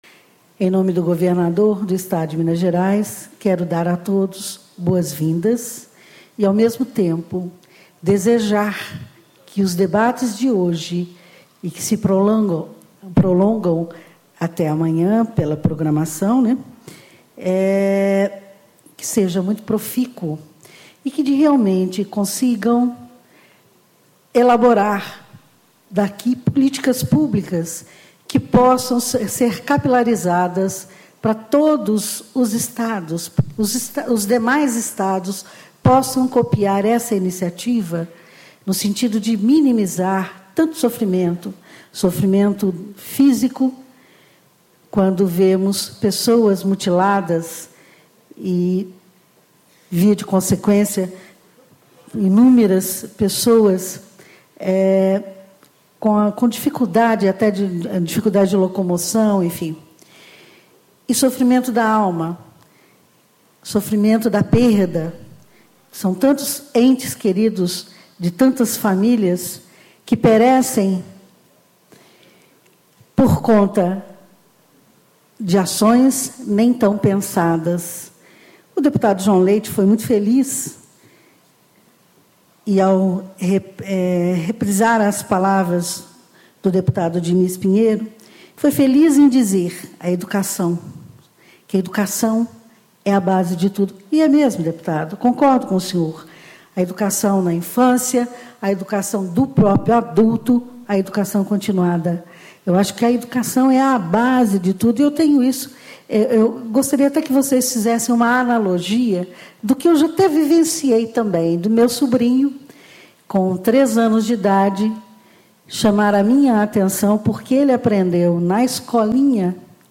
Maria de Lurdes Camilli, Chefe em exercício da Polícia Civil de Minas Gerais, Representante do Governador de Minas, Antonio Augusto Anastasia
Discursos e Palestras